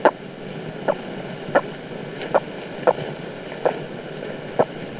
These are portraits of dearly departed chickens who lived with us; please enjoy some chicken noises recorded straight from the barn as you peruse!
Chicken Noises - Click to Listen My Sweet Gibson